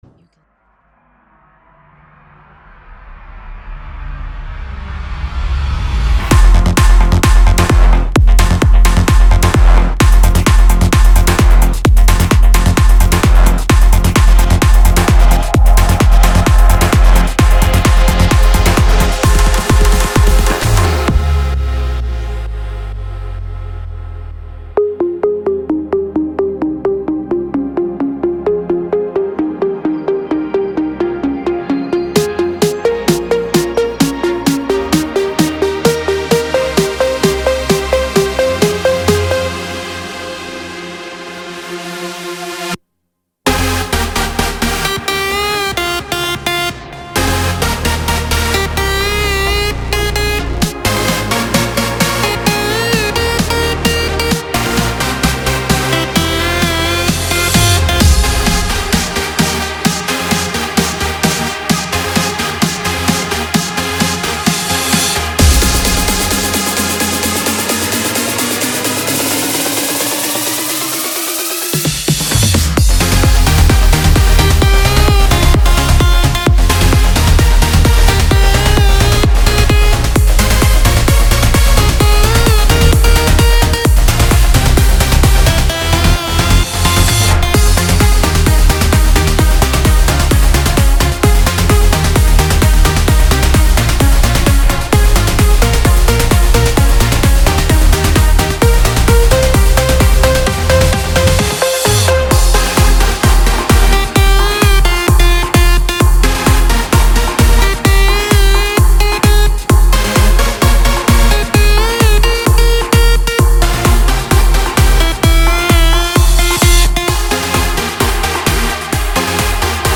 Dance/Electronic
trance